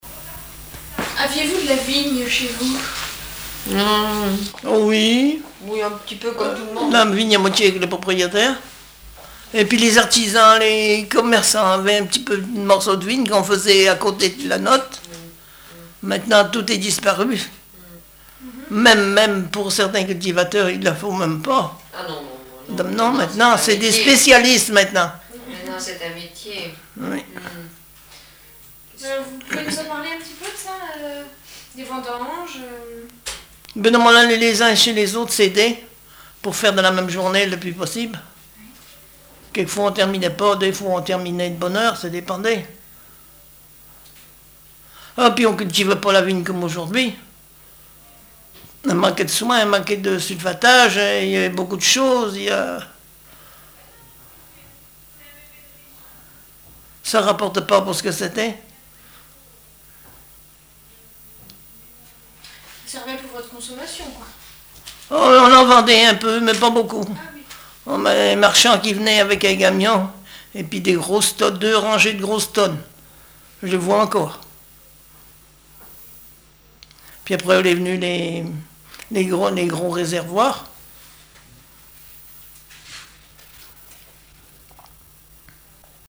Témoignages sur l'agriculture et les rogations
Catégorie Témoignage